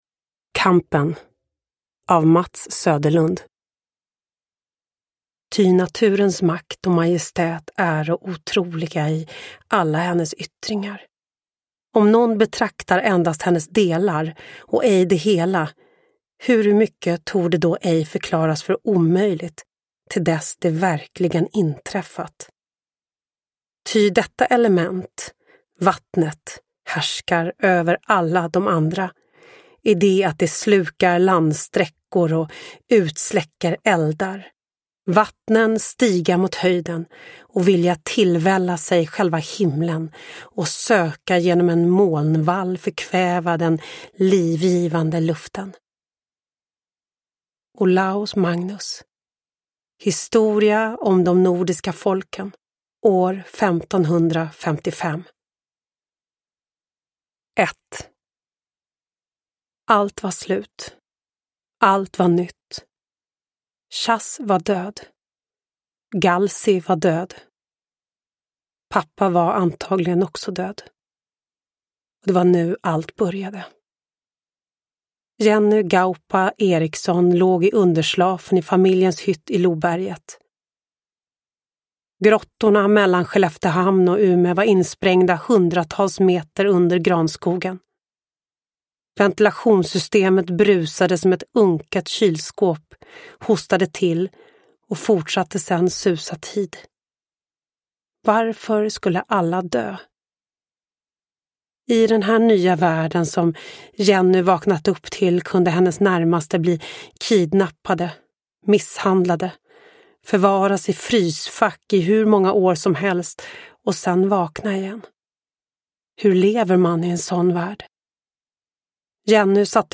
Kampen – Ljudbok – Laddas ner
Uppläsare: Lo Kauppi